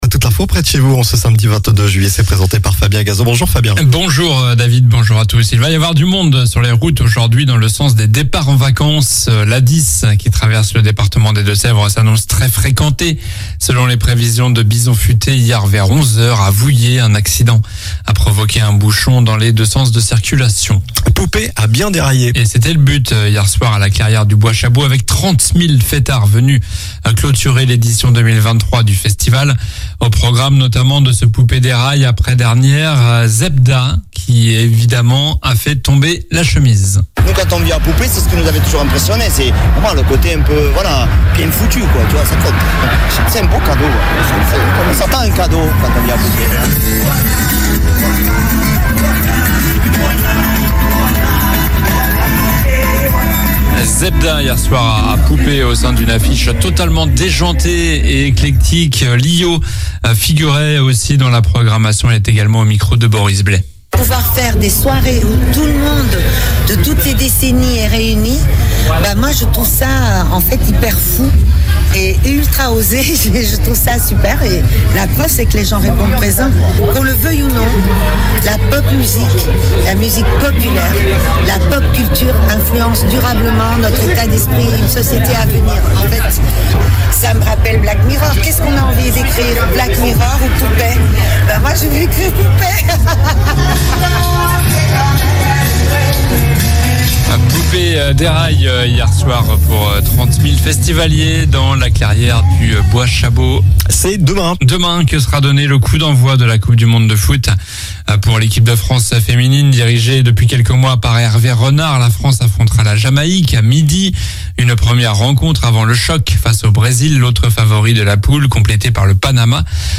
Journal du samedi 22 juillet (matin)